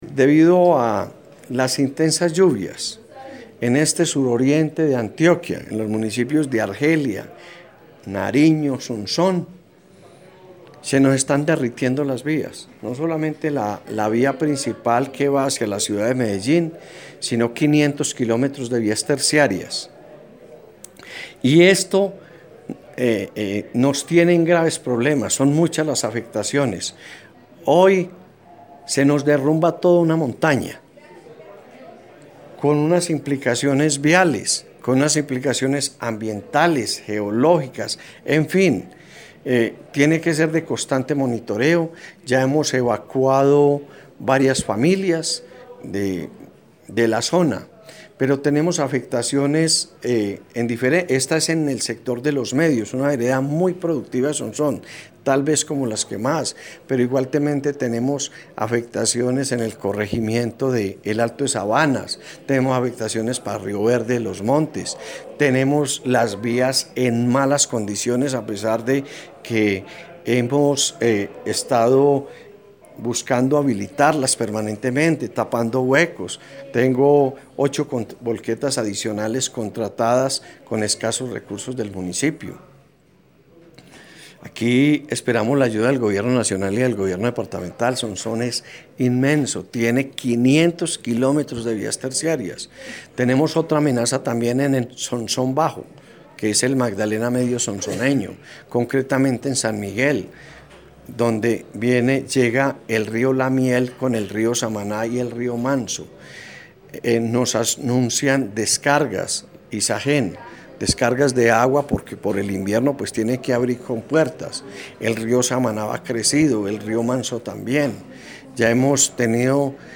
Audio, declaraciones del alcalde de Sonsón.
Alcalde-de-Sonsón-decretó-Calamidad-Pública.mp3